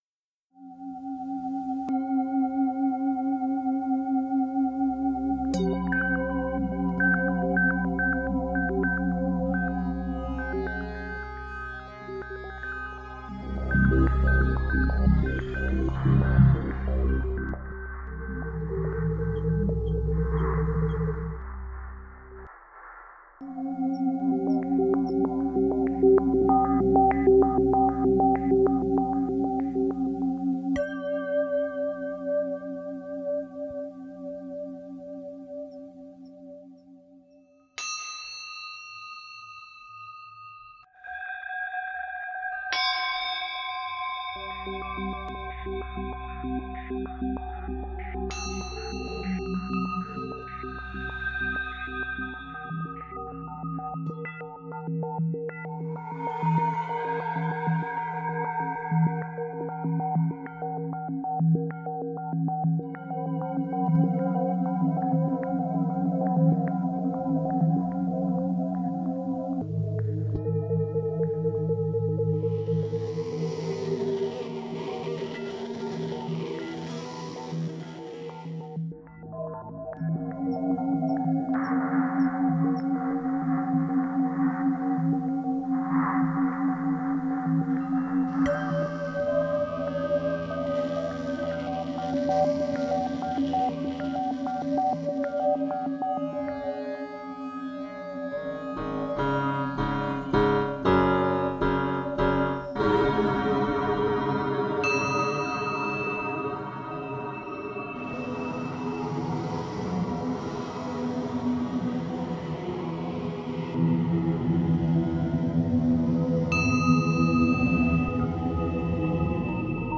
bells, keys
guitar
treated sounds